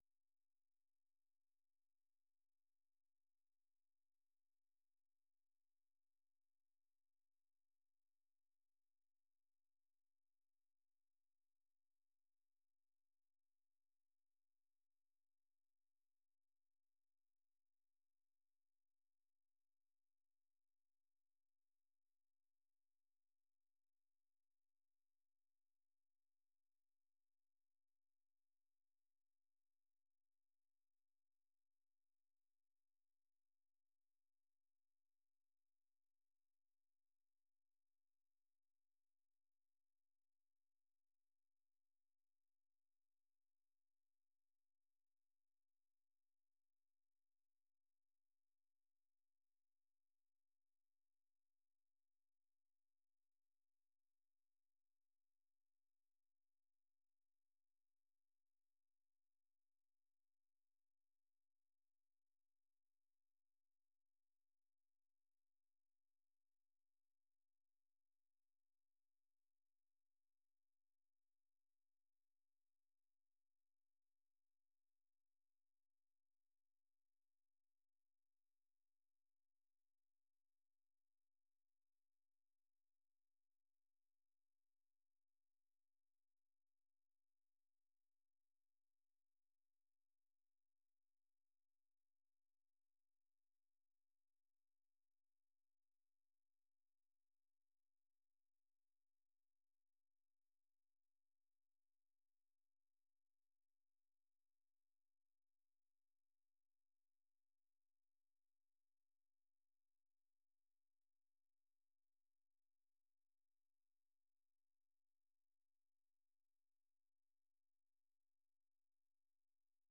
생방송 여기는 워싱턴입니다 저녁